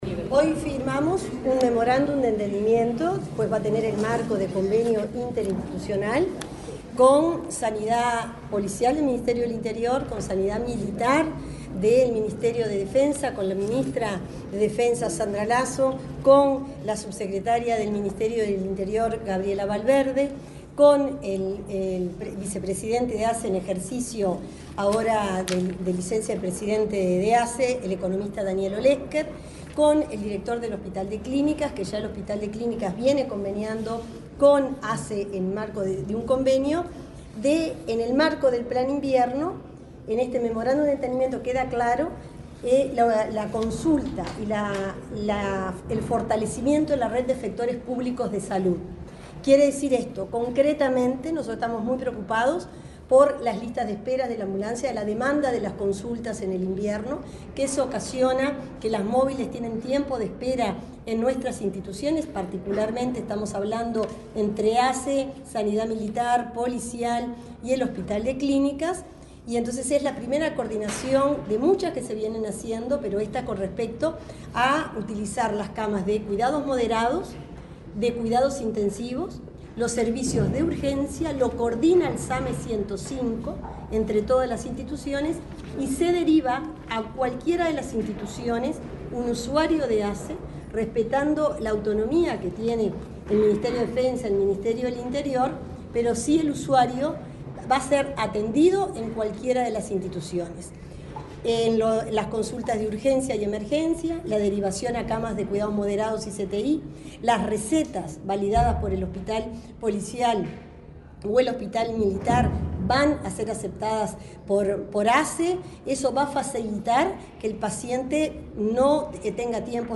Declaraciones de la titular del MSP, Cristina Lustemberg, y el vicepresidente de ASSE, Daniel Olesker
La titular del Ministerio de Salud Púbica (MSP), Cristina Lustemberg, y el vicepresidente de la Administración de los Servicios de Salud del Estado (ASSE), Daniel Olesker, dialogaron con la prensa, luego del acto de firma de un memorando de entendimiento para reforzar la respuesta del sistema sanitario durante el Plan Invierno.